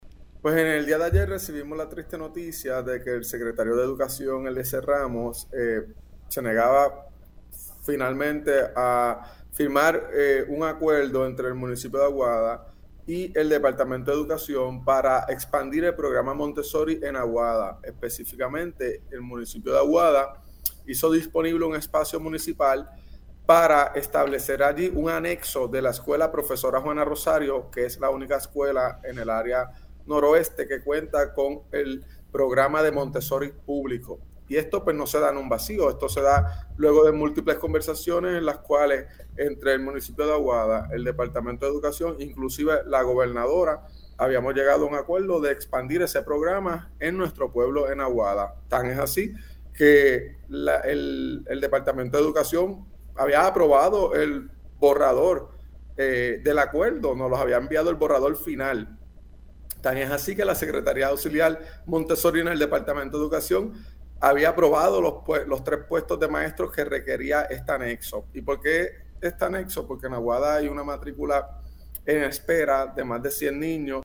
Habíamos llegado a un acuerdo de expandir ese programa en nuestro pueblo de Aguada“, explicó Cortés en Pega’os en la Mañana.